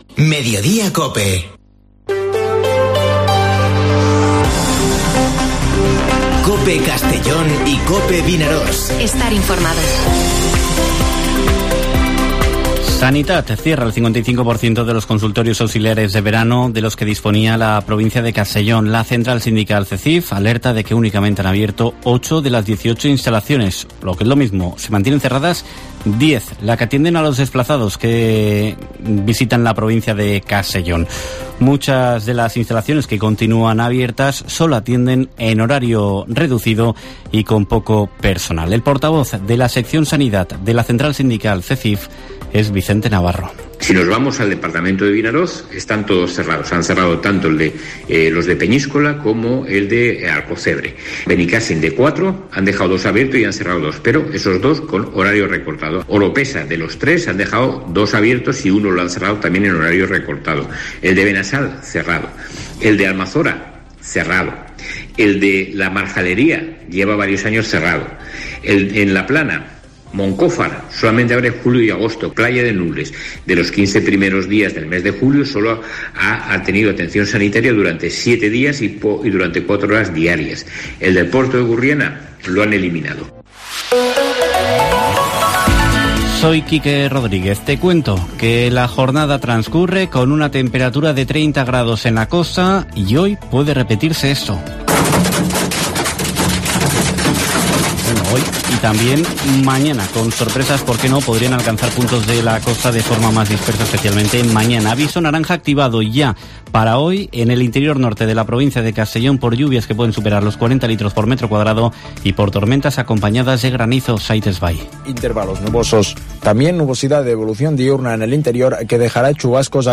Informativo Mediodía COPE en la provincia de Castellón (29/07/2022)